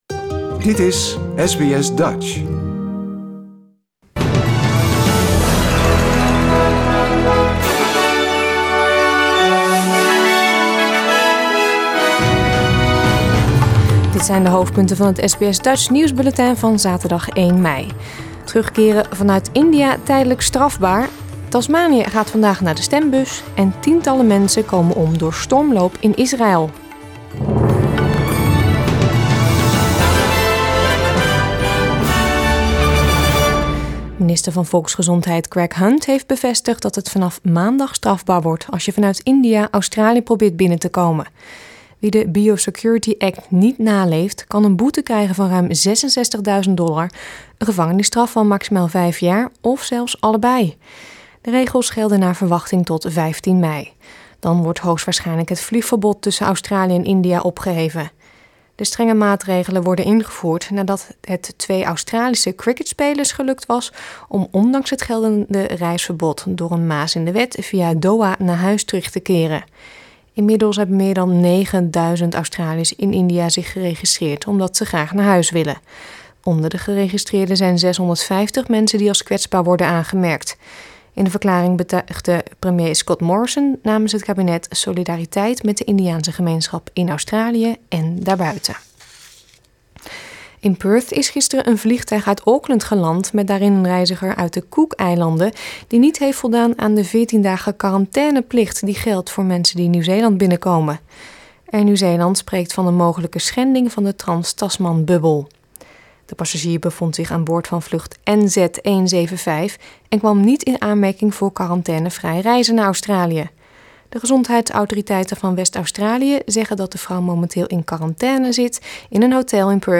Nederlands/Australisch SBS Dutch nieuwsbulletin van zaterdag 1 mei 2021